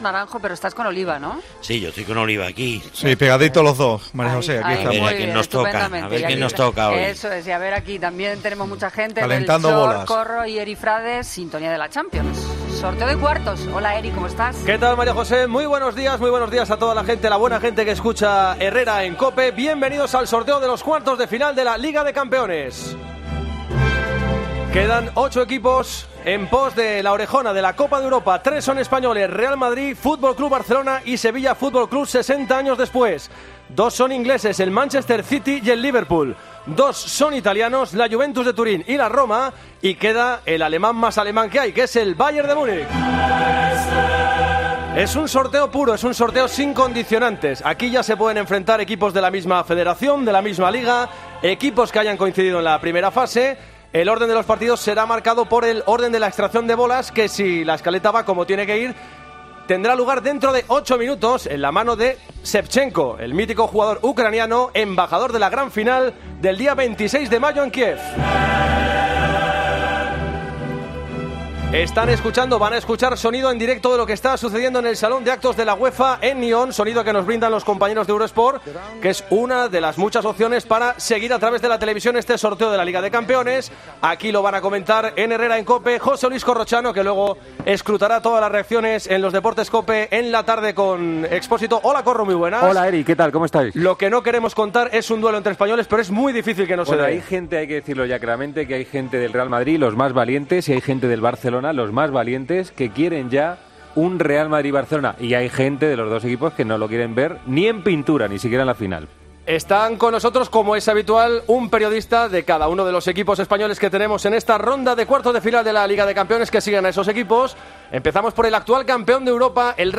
AUDIO: Escucha la retransmisión del sorteo de emparejamientos de los cuartos de final de la Champions League que se ha celebrado este viernes en Nyon.